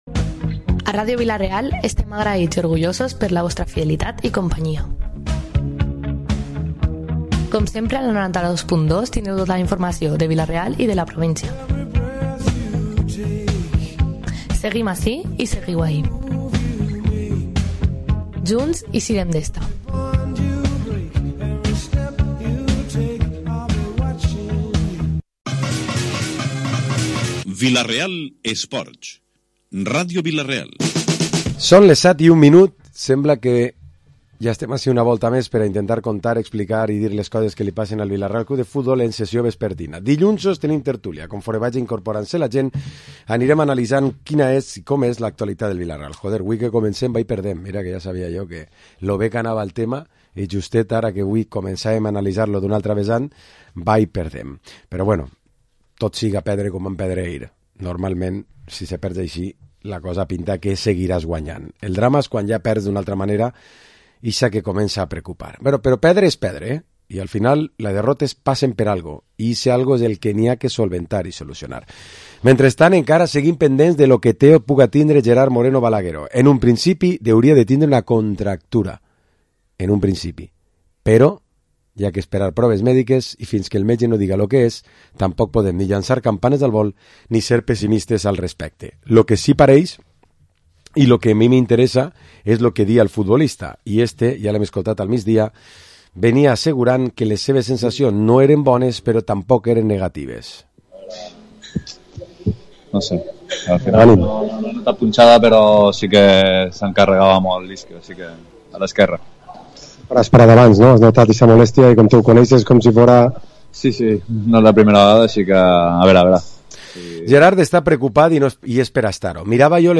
Programa esports tertúlia dilluns 12 de Setembre